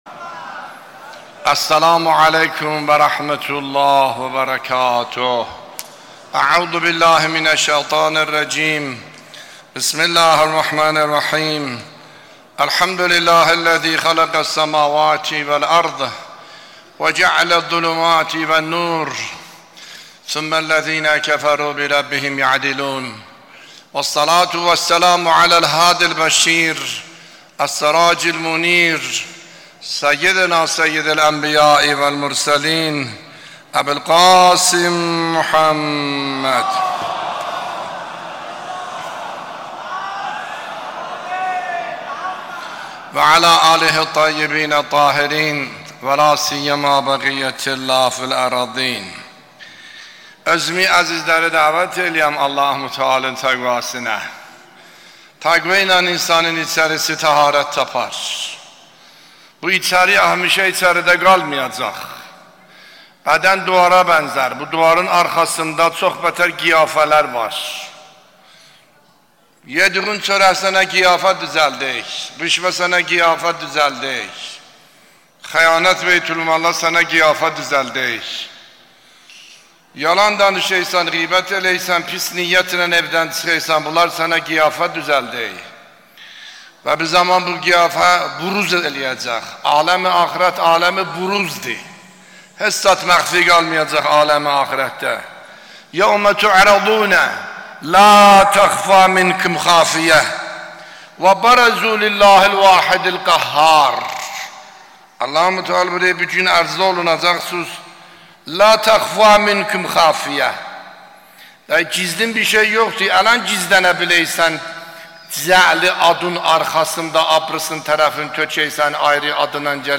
بیانات آیت الله سید حسن عاملی نماینده ولی فقیه و امام جمعه اردبیل در خطبه های نماز جمعه در 22 اردیبهشت 1402